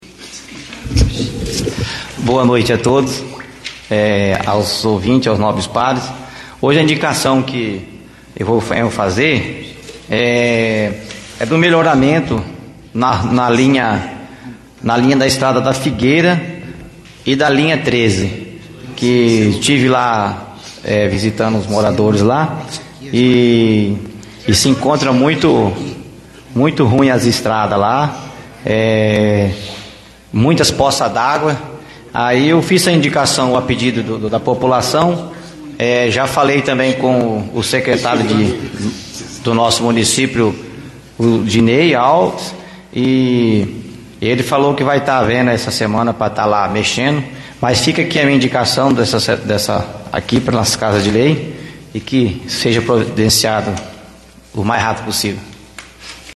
Em sessão ordinária realizada em 19/06, o Vereador Robinho apresentou indicação solicitando a recuperação das estradas da linha da Figueira e Linha 13.